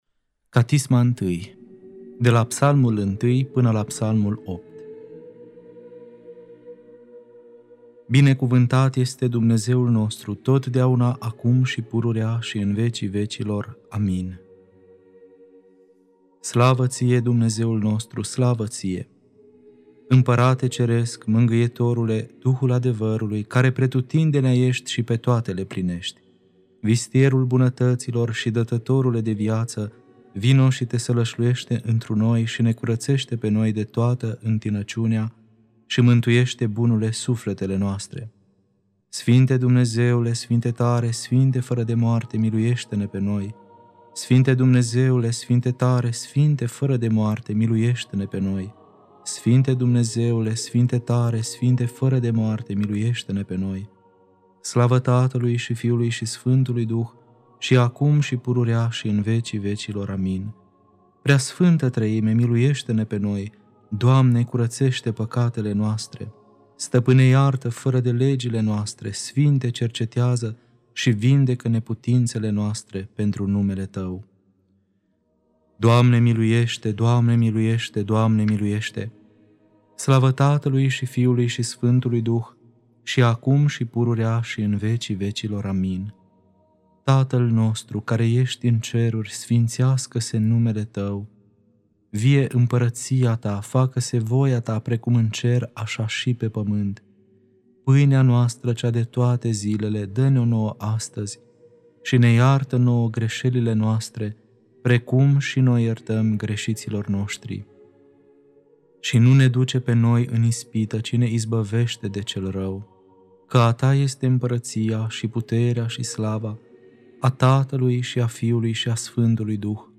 Catisma I (Psalmii 1-8) Lectura